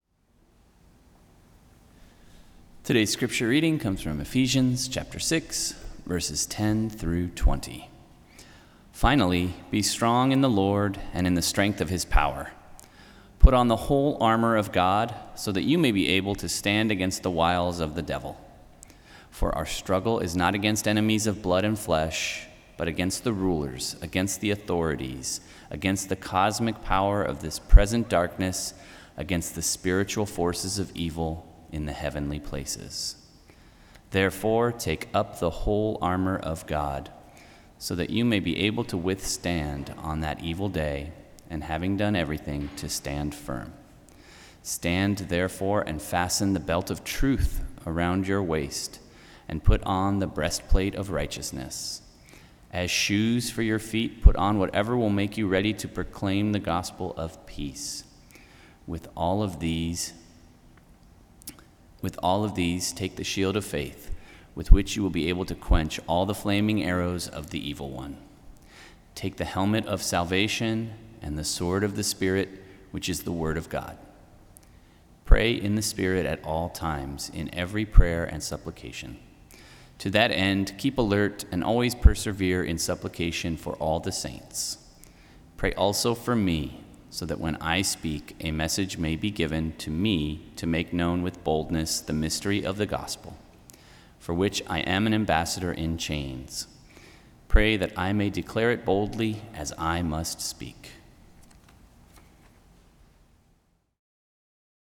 Service of Worship
Scripture Reading — Ephesians 6:10-20 (NRSV)